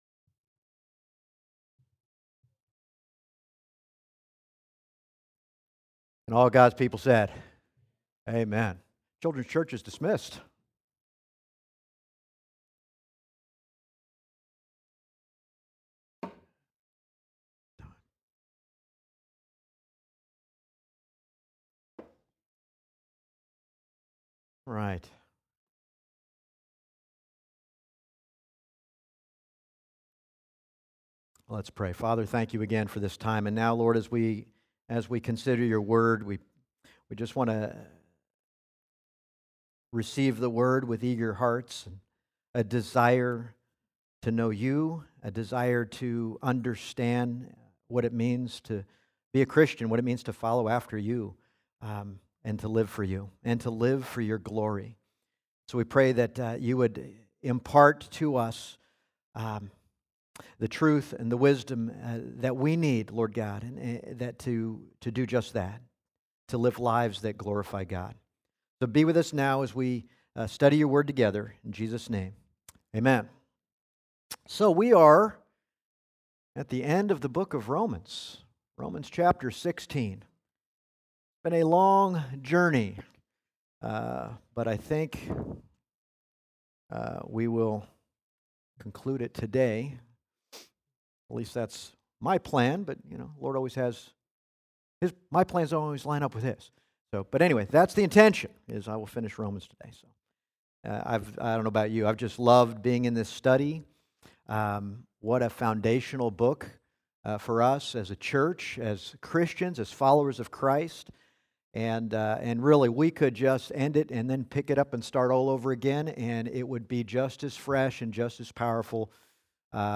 Passage: Romans 16:25-27 Service Type: Sunday Morning